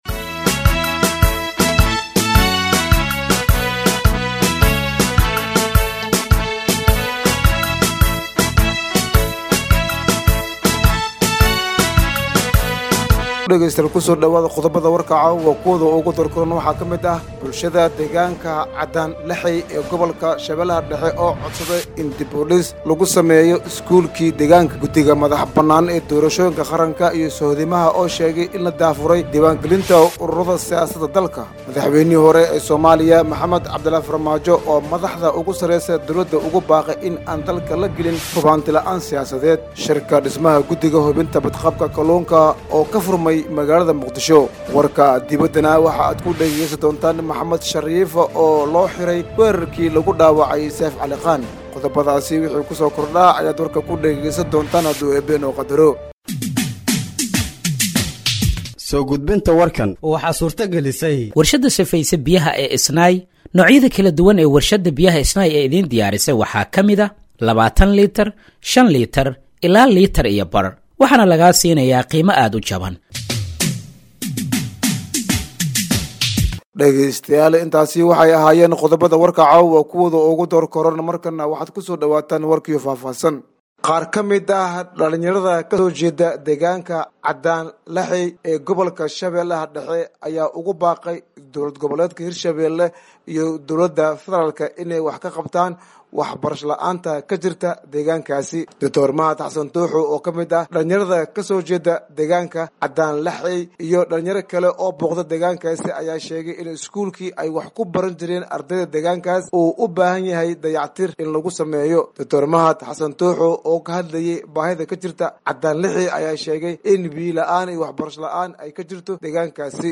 Dhageeyso Warka Habeenimo ee Radiojowhar 20/01/2025